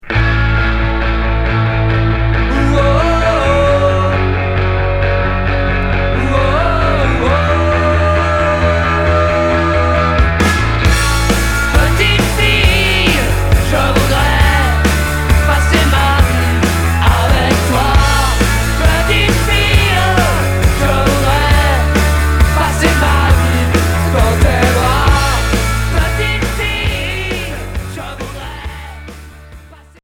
Rock Quatrième 45t retour à l'accueil